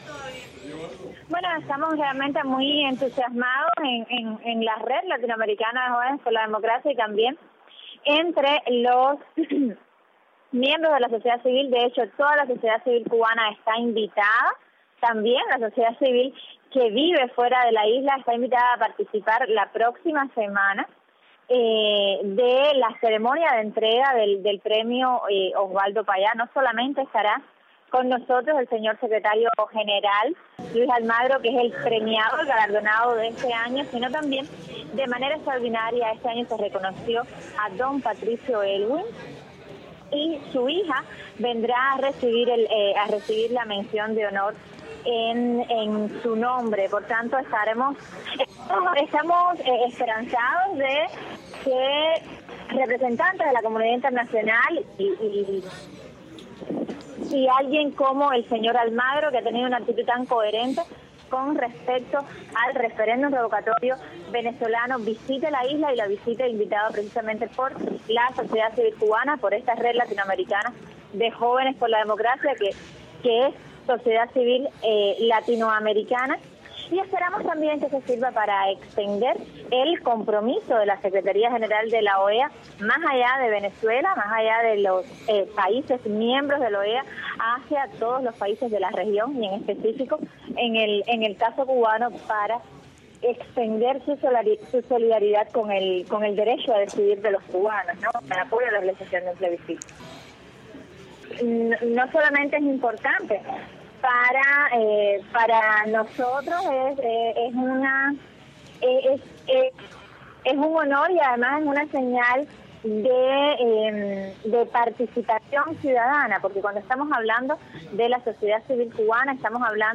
Rosa María Payá entrevistada